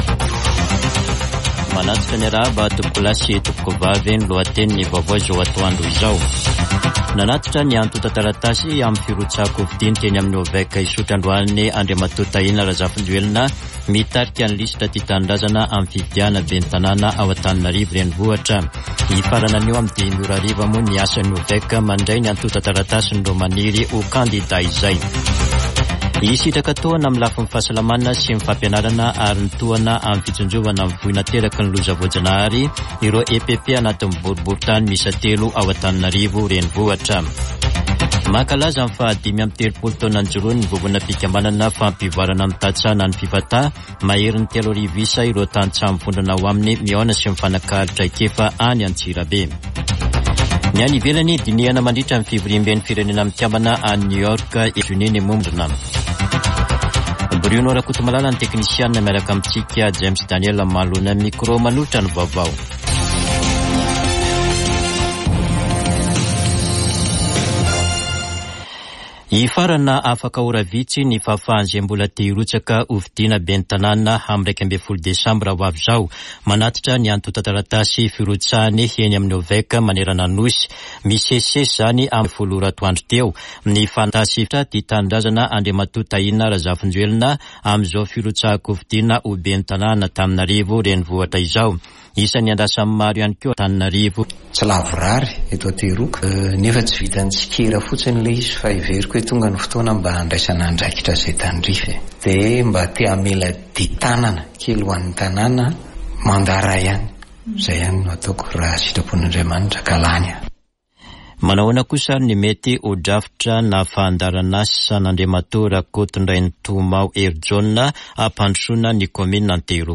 [Vaovao antoandro] Alakamisy 26 septambra 2024